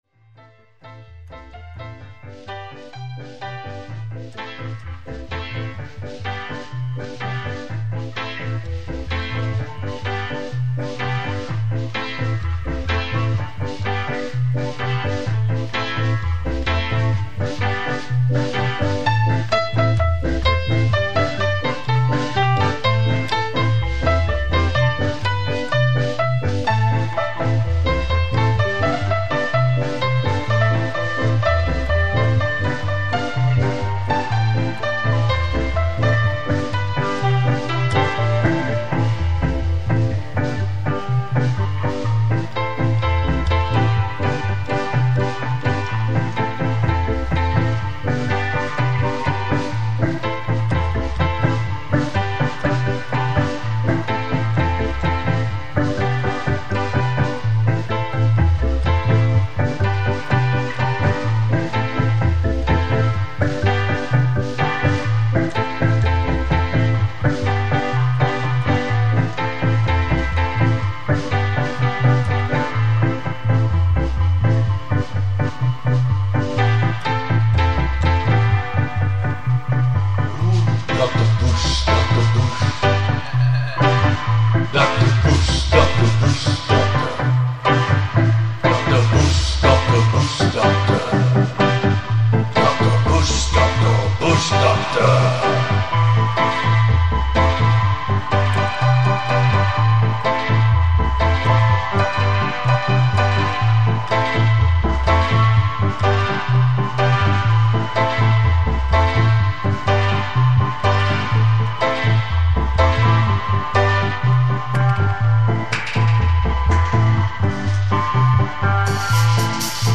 Jam